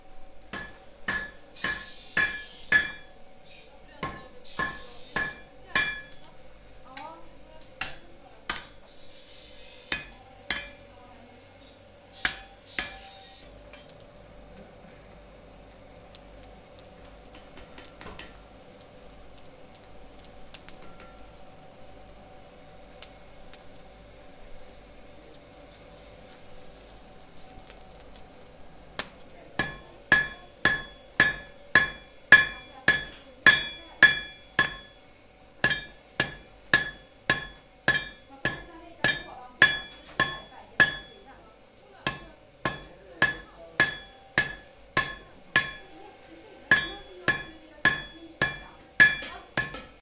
blacksmith-3.ogg